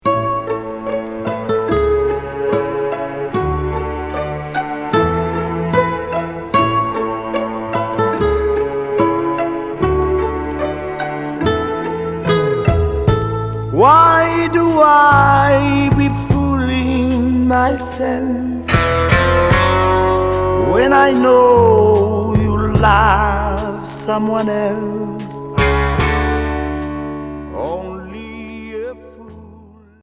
R'n'b soul